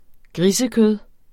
grisekød substantiv, intetkøn Bøjning -et Udtale [ ˈgʁisə- ] Betydninger kød fra gris Synonymer svinekød flæskekød Grisekødet bliver lavet til pølser, rillette, bacon og koteletter Pol2015 Politiken (avis), 2015.